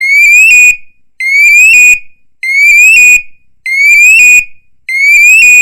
Categoria Alarmes